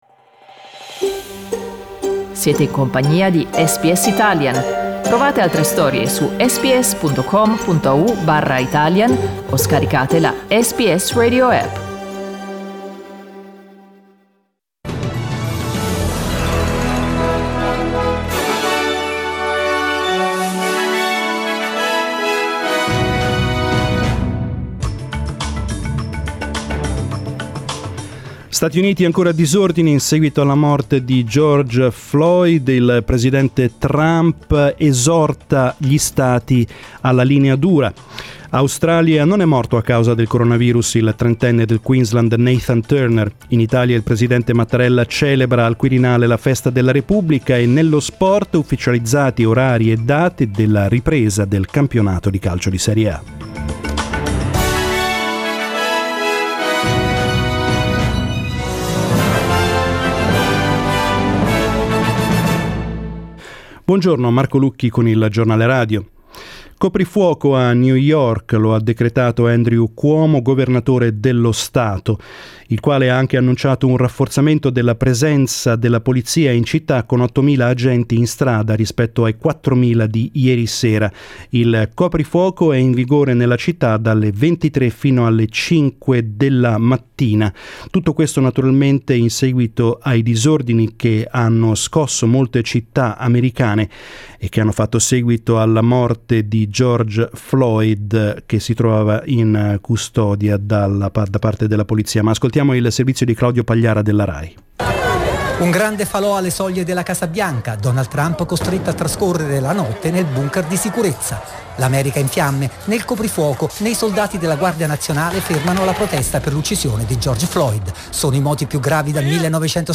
Our news bulletin in Italian